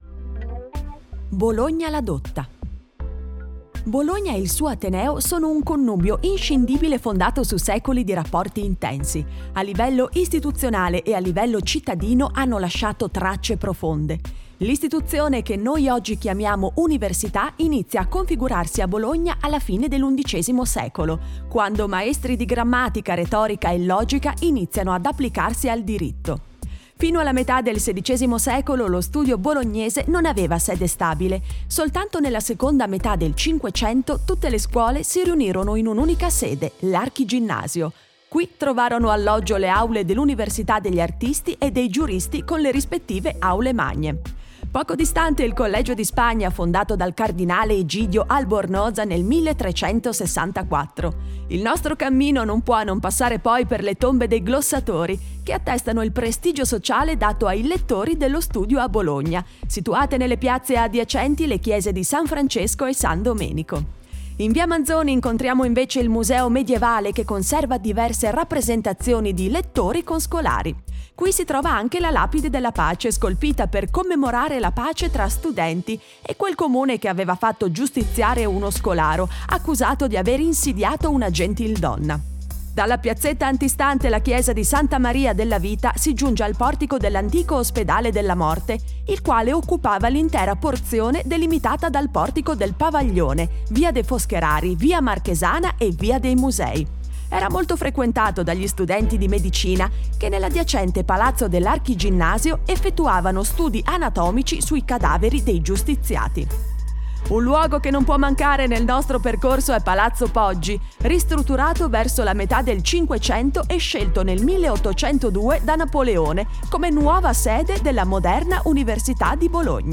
Audioguida - Le torri di Bologna tra ieri e oggiDownload